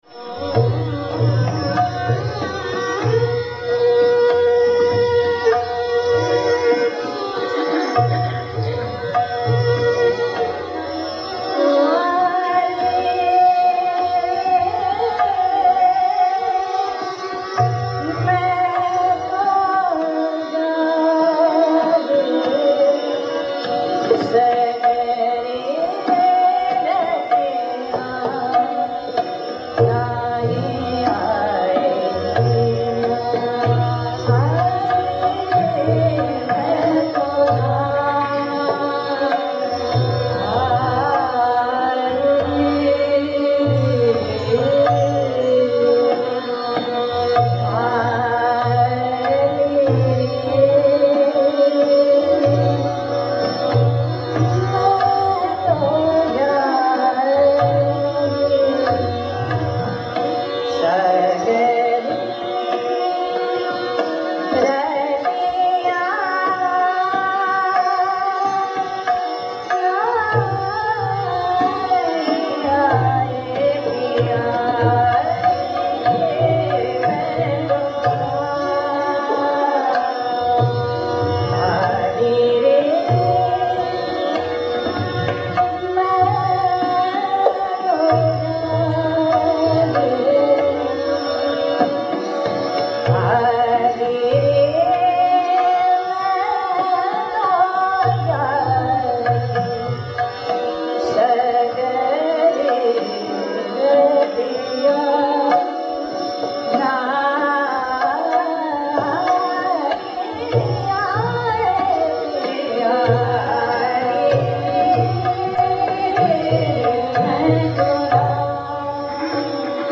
Ragas of Indian Classical Music.
Indian Classical Music